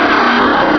Cri de Flobio dans Pokémon Rubis et Saphir.